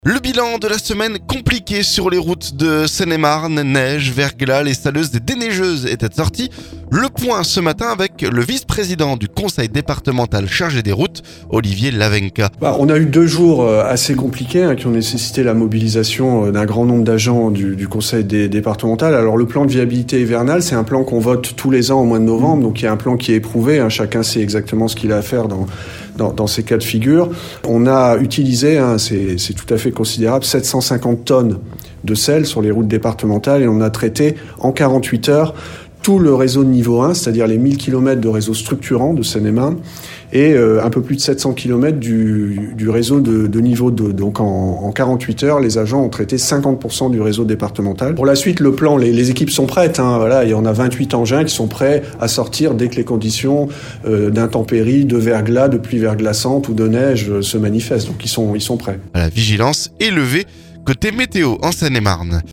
Le point ce vendredi avec le vice-président du Conseil départemental chargé des routes, Olivier Lavenka.